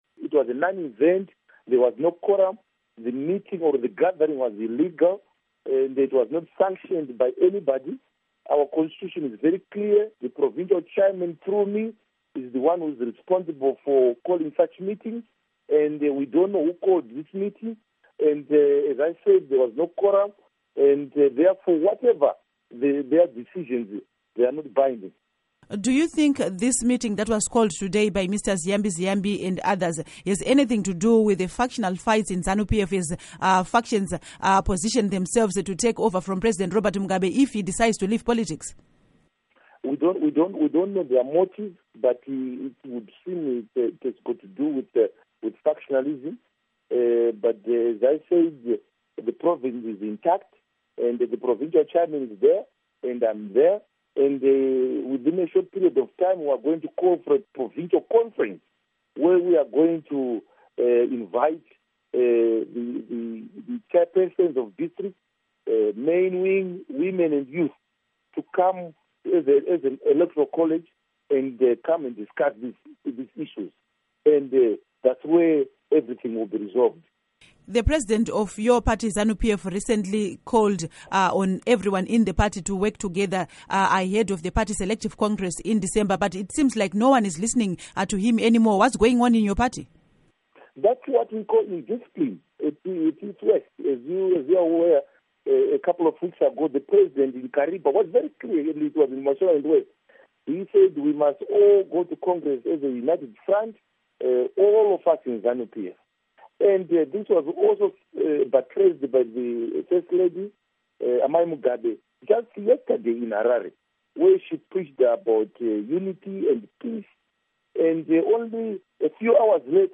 Interview With Kindness Paradza